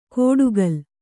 ♪ kōḍugal